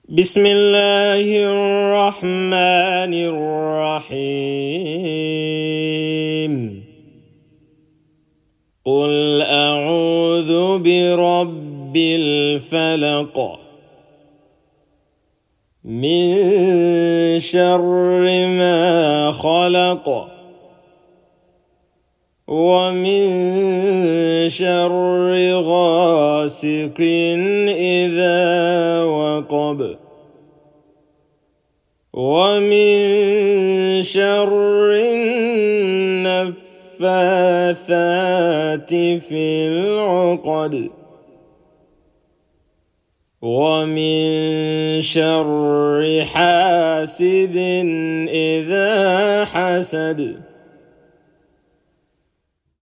|thumb|right|알-팔라크(Al-Falaq) 무자와드(mujawwad)로 낭송